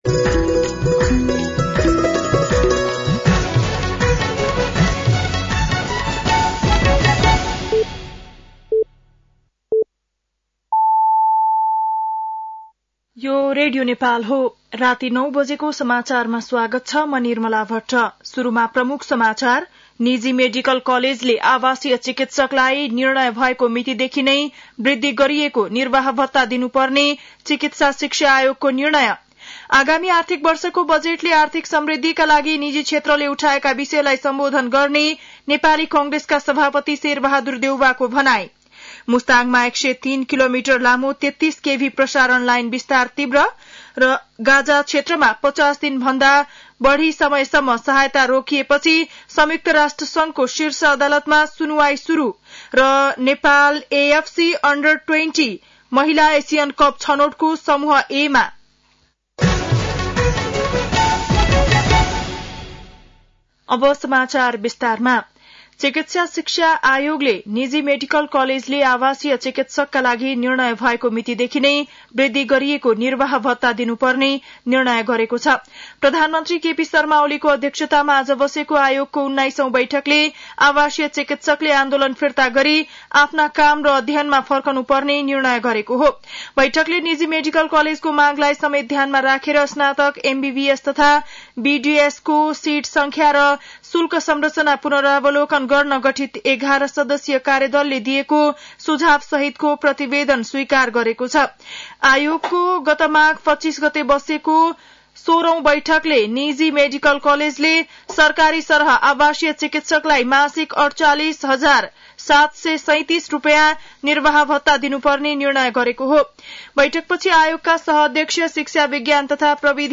बेलुकी ९ बजेको नेपाली समाचार : १५ वैशाख , २०८२